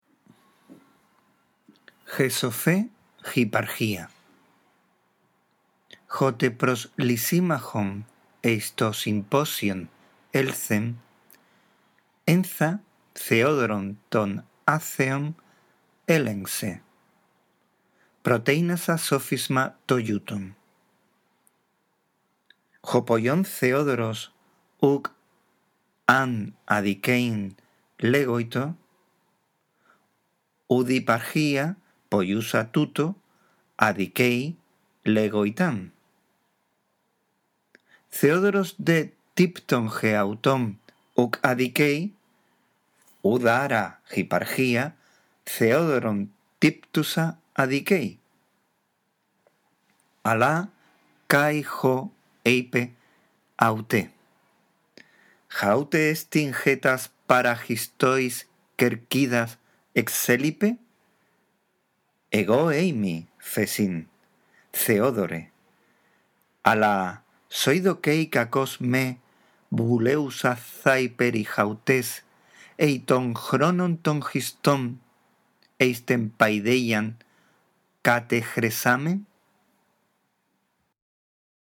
La audición de este archivo te ayudará en la práctica de la lectura del griego